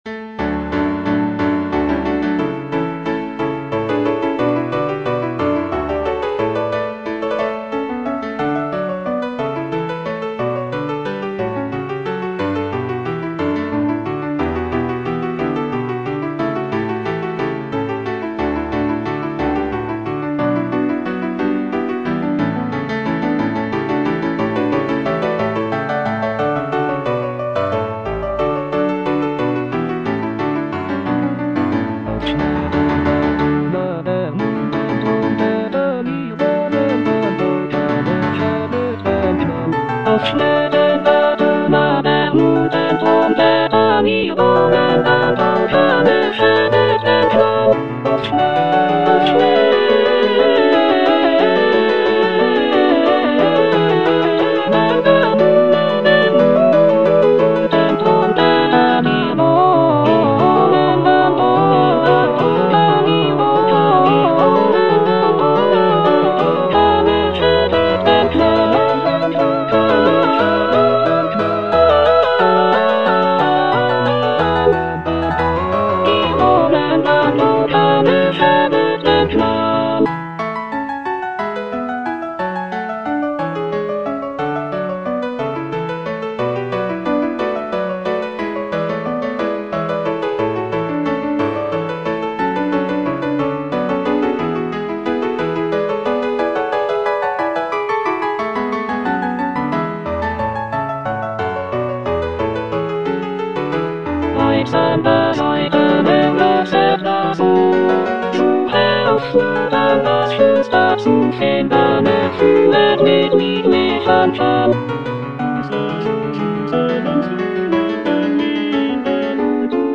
Choralplayer playing Cantata
It is a festive and celebratory work featuring lively trumpet fanfares and joyful choruses.